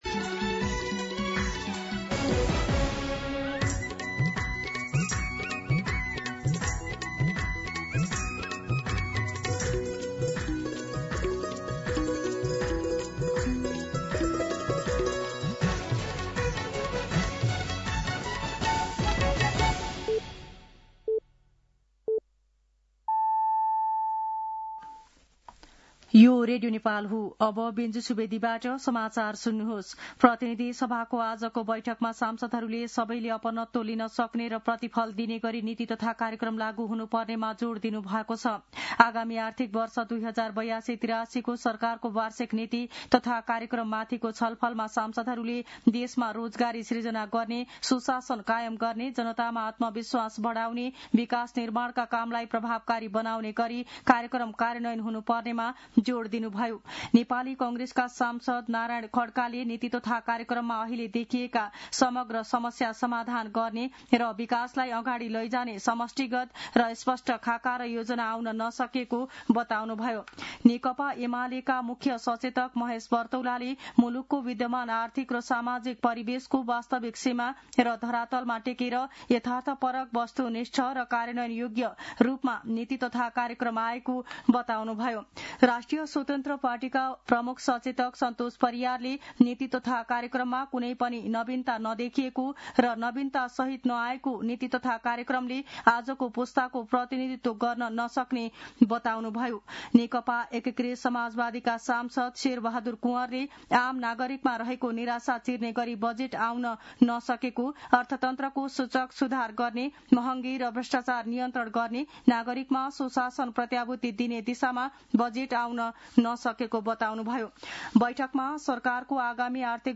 दिउँसो १ बजेको नेपाली समाचार : २८ वैशाख , २०८२
1-pm-Nepali-News-1.mp3